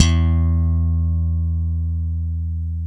Index of /90_sSampleCDs/Roland - Rhythm Section/BS _E.Bass v_s/BS _P.Bs _ Slap
BS  SLAPS 08.wav